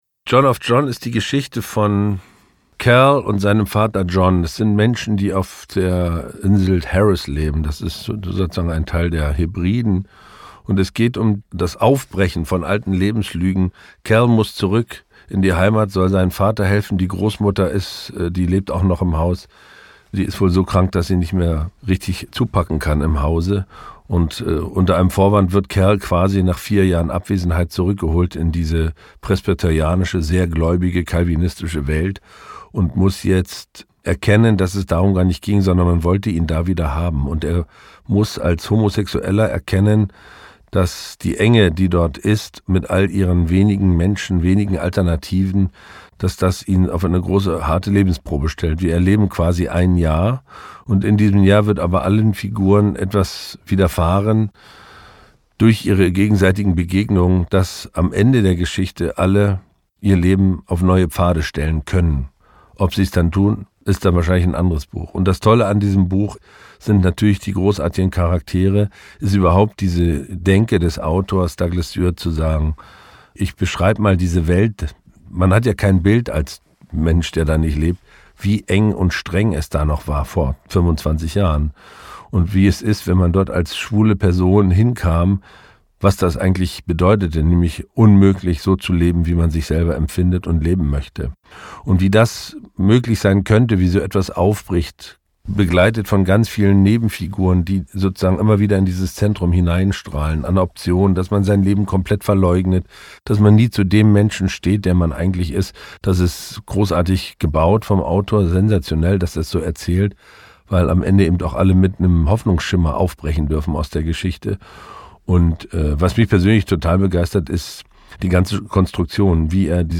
Huebner_Interview_Stuart_JohnofJohn.mp3